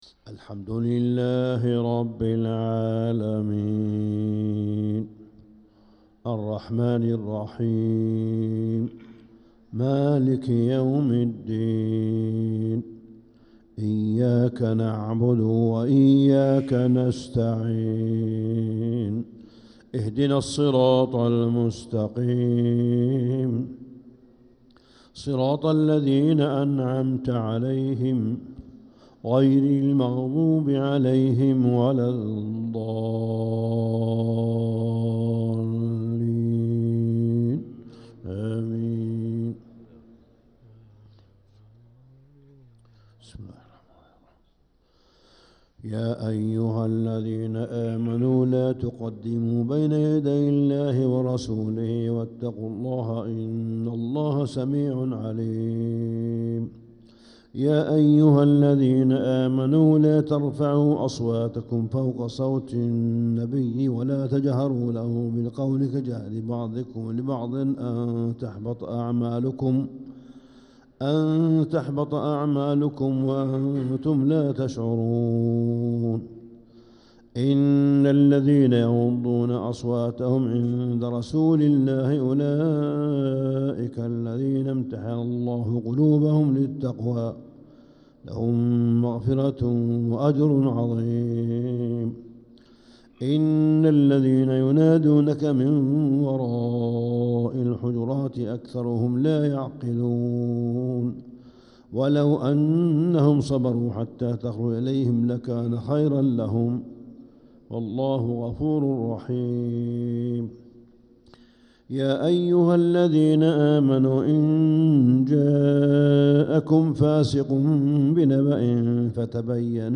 فجر السبت 8-9-1446هـ فواتح سورة الحجرات 1-13 | Fajr prayer from Surat al-Hujurat 8-3-2025 > 1446 🕋 > الفروض - تلاوات الحرمين